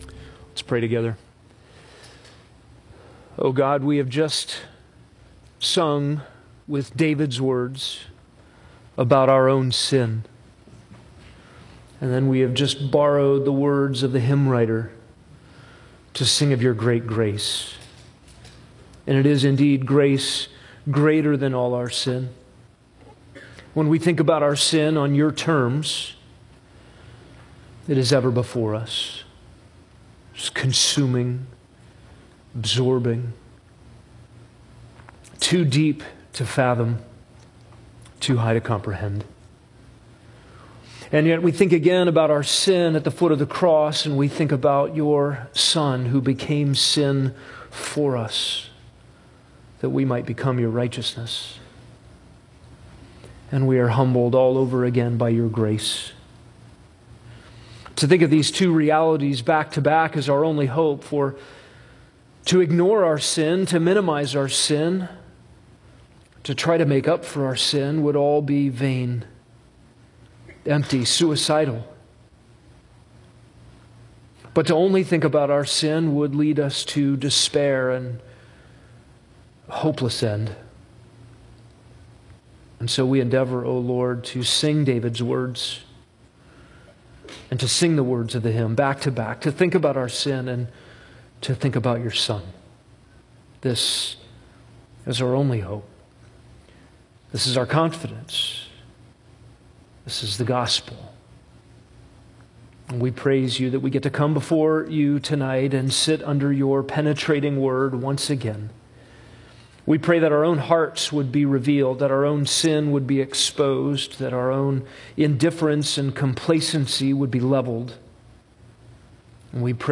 Weekly sermons from Grace Bible Church in Tempe, Arizona